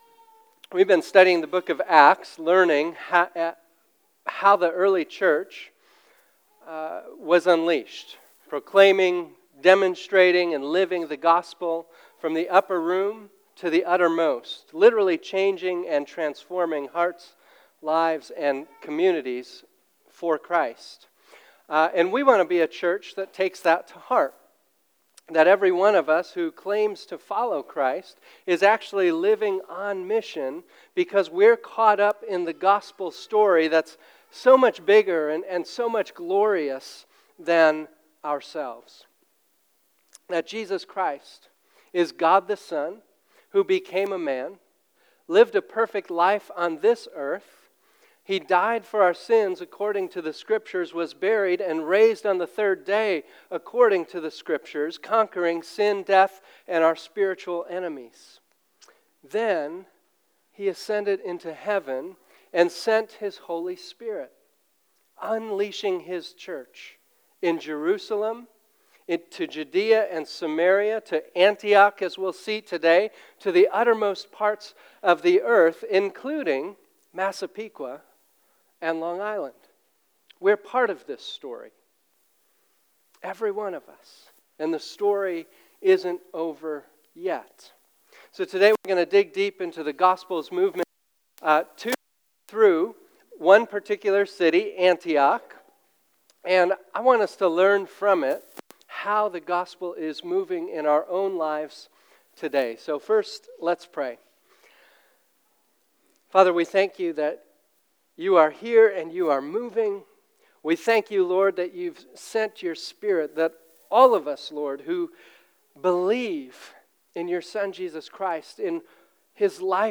Today we continue our sermon series, Acts: From the Upper Room to the Utter Most.